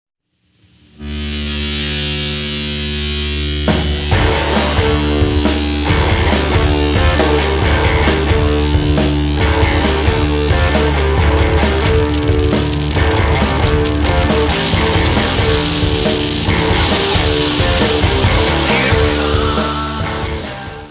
: stereo; 12 cm + folheto
Área:  Pop / Rock